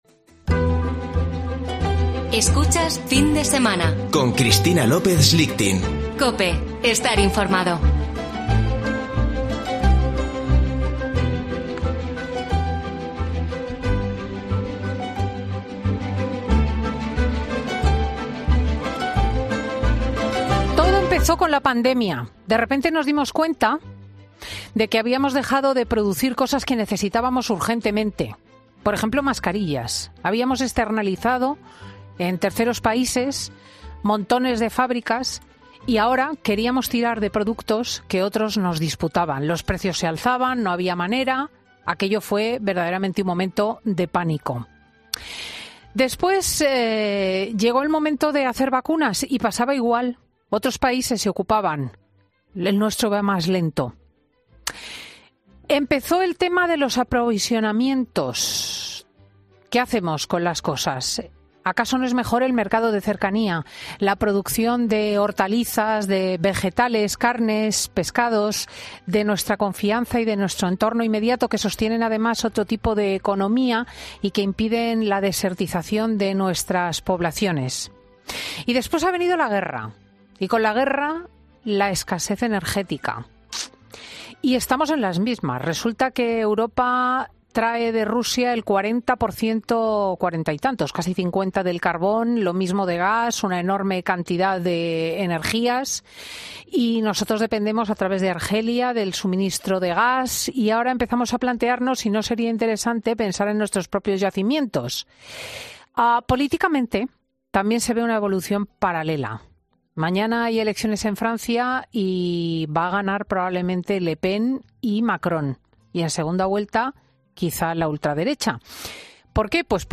Tertulia de chicos en Fin de Semana con Cristina